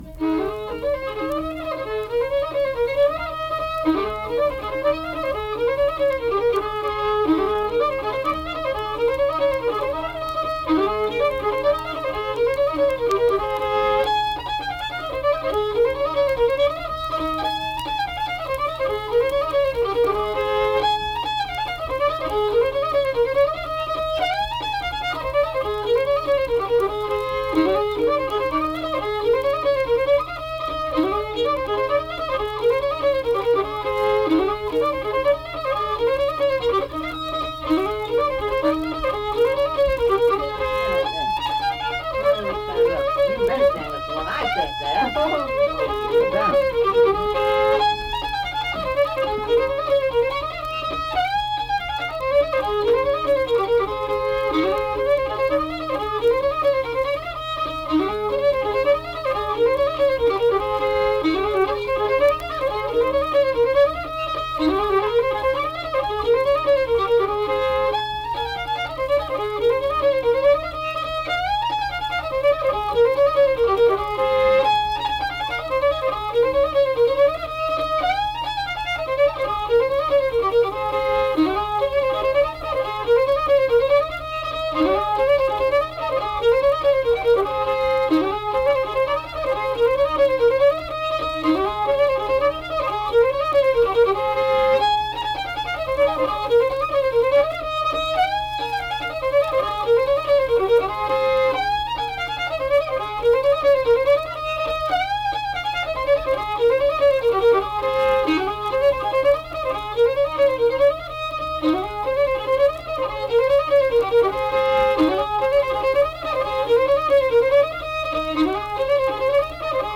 Unaccompanied fiddle music and accompanied (guitar) vocal music
Instrumental Music
Fiddle
Braxton County (W. Va.)